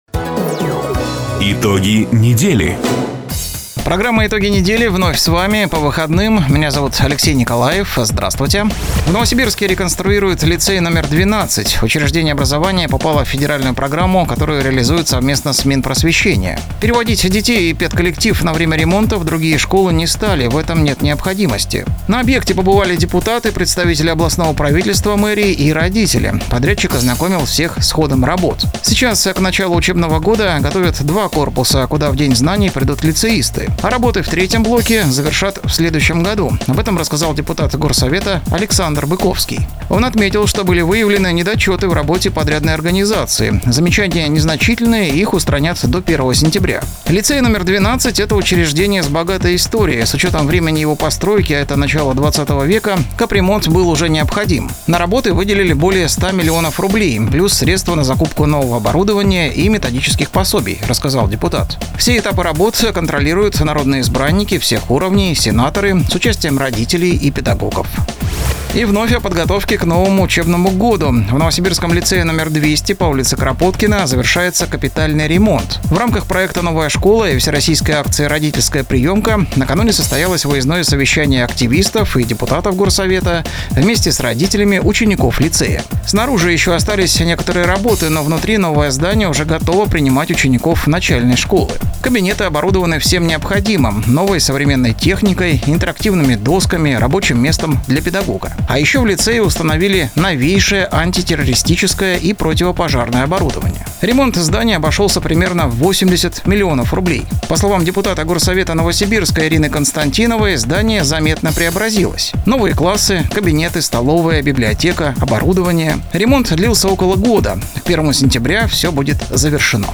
Запись программы "Итоги недели", транслированной радио "Дача" 24 августа 2024 года.